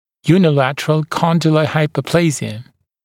[juːnɪ’lætərəl ‘kɔndələ ˌhaɪpəˈpleɪzɪə][йу:ни’лэтэрэл ‘кондэлэ ˌхайпэˈплэйзиэ]односторонняя мыщелковая гиперплазия